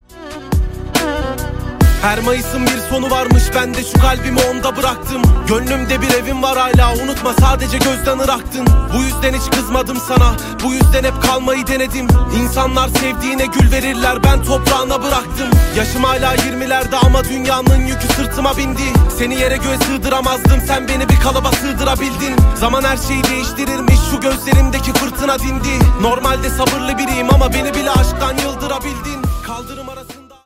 Rap/Hip-Hop